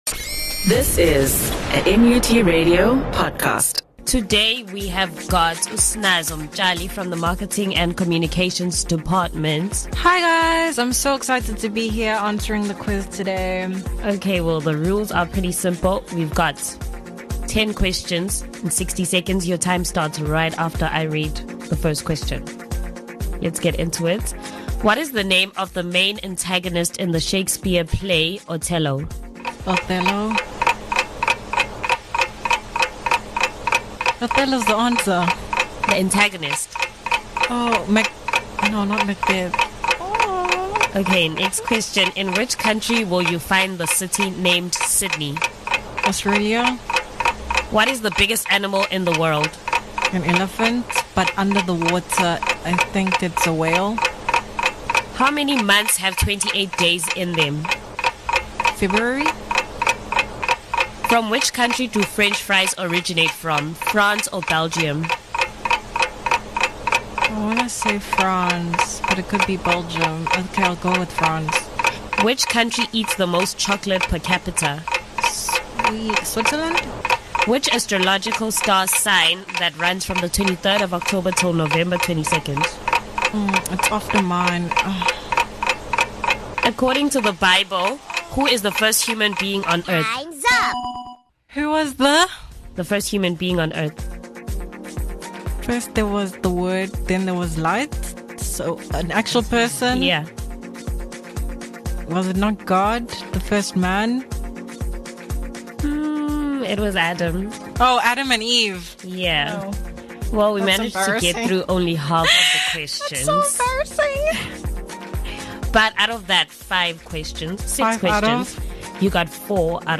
A one minute quiz, (sixty second - ten questions) is a knowledge-testing activity where a guest answers ten questions in a minute.